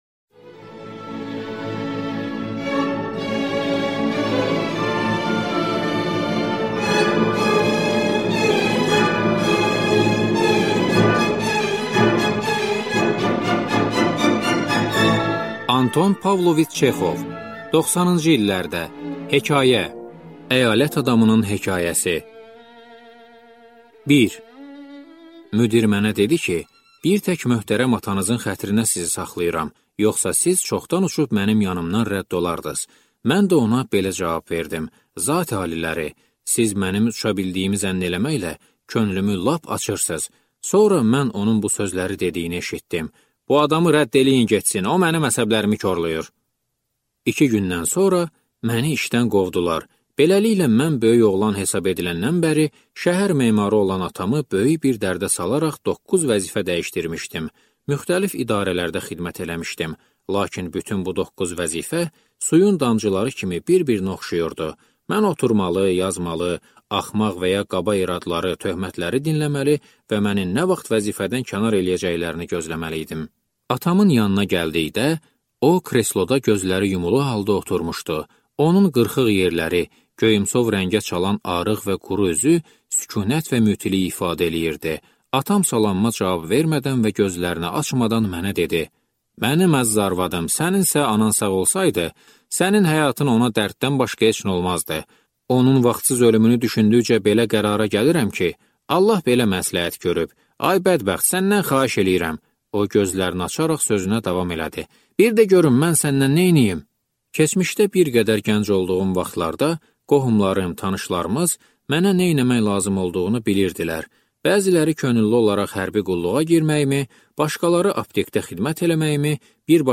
Аудиокнига Əyalət adamının hekayəsi | Библиотека аудиокниг